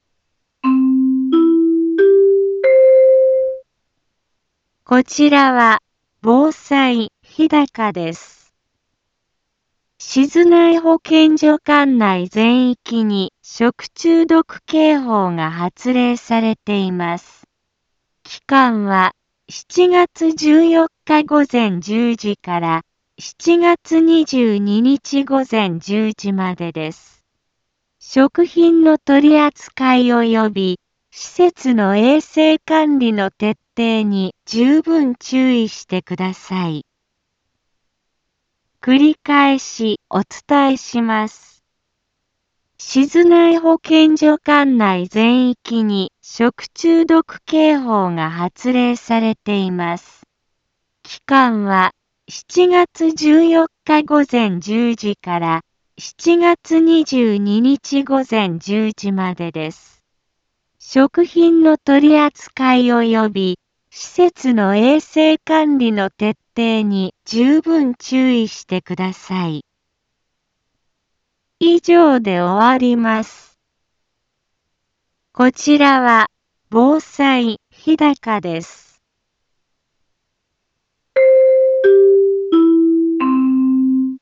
一般放送情報
BO-SAI navi Back Home 一般放送情報 音声放送 再生 一般放送情報 登録日時：2025-07-14 15:03:01 タイトル：食中毒警報の発令について インフォメーション： こちらは、防災日高です。 静内保健所管内全域に食中毒警報が発令されています。